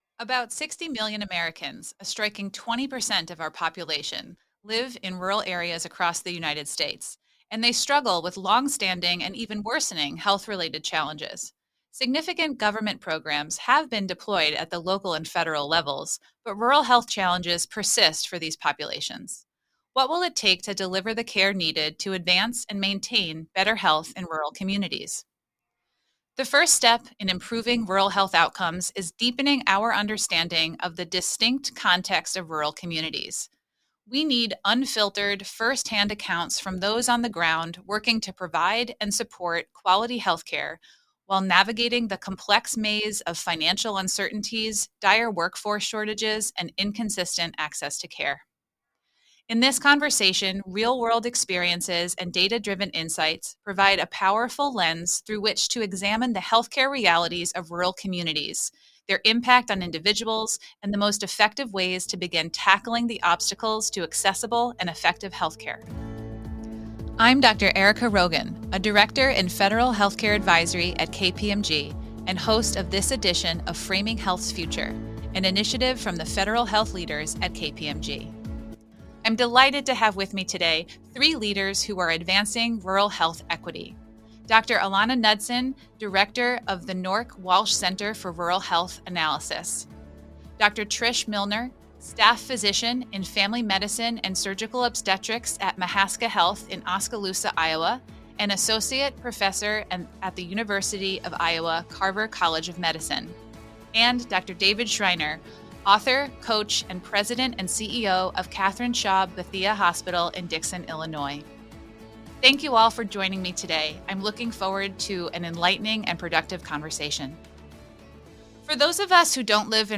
In this conversation, real-world experiences and data-driven insights provide a powerful lens through which to examine the healthcare realities of rural communities, their impact on individuals, and the most effective ways to begin tackling rural health challenges.